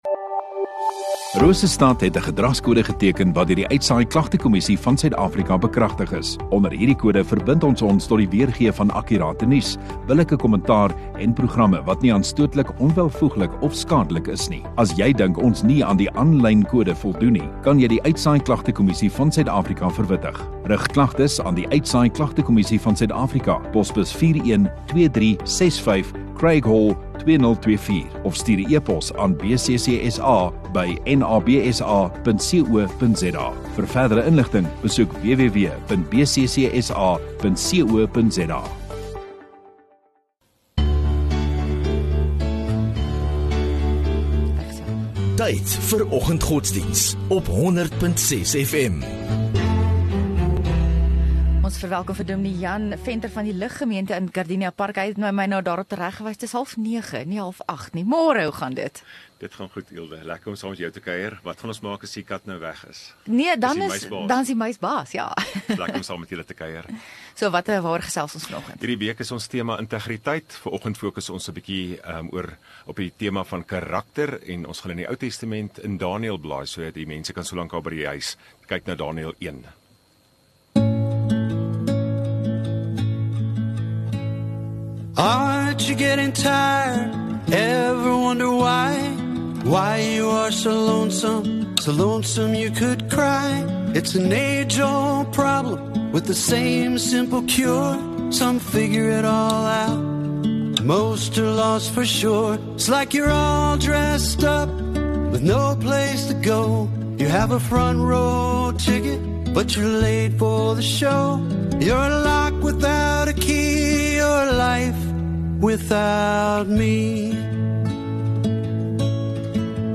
6 Nov Woensdag Oggenddiens